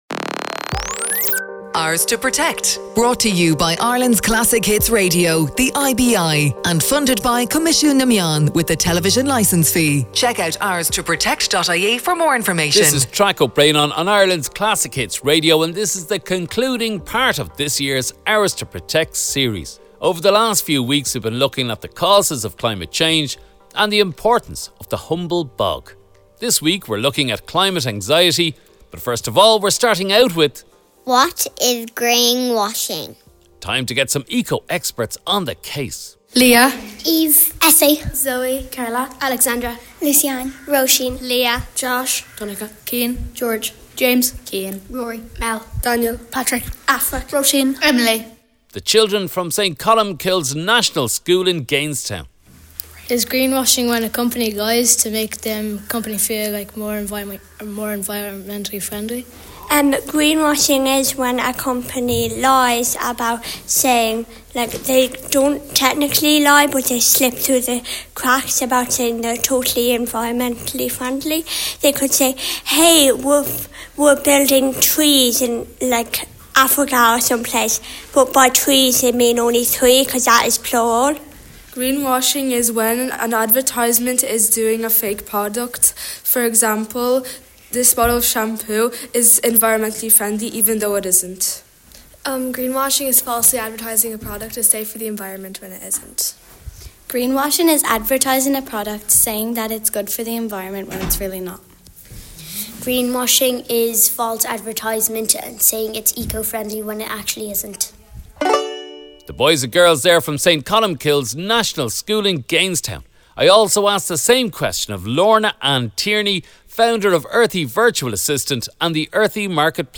Ours to Protect on Ireland’s Classic Hits Radio